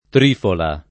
trifola [ tr & fola ] s. f.